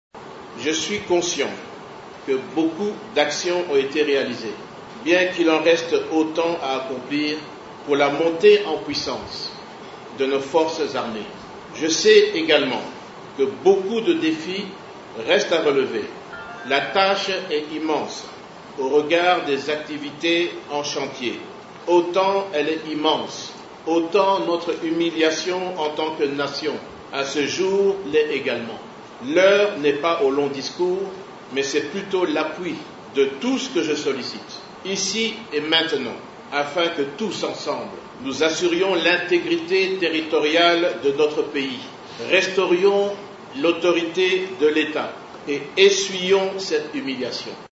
Il l’a lancé cet appel lors de la cérémonie de remise et reprise avec le ministre sortant Gilbert Kabanda. C’était au sein de l'amphithéâtre du Collège des hautes études des stratégies et de défense à Kinshasa.